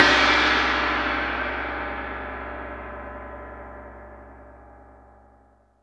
GONG 3.wav